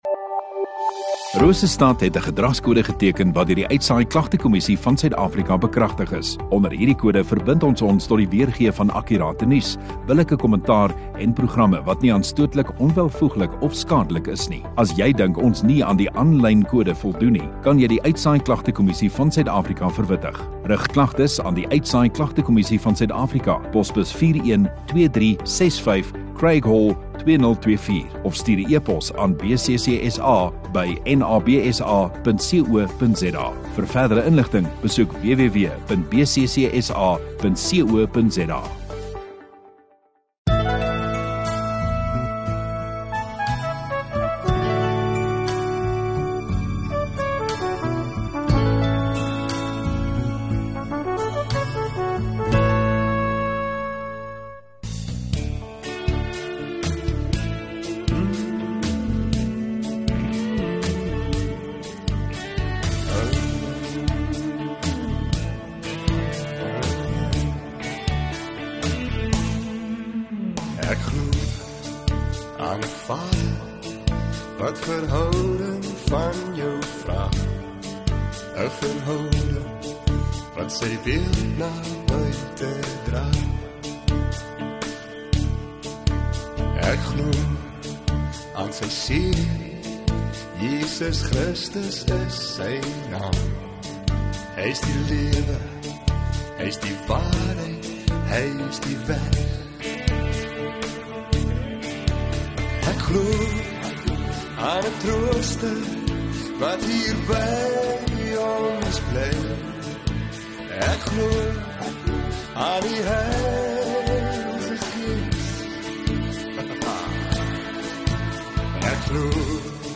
7 Oct Saterdag Oggenddiens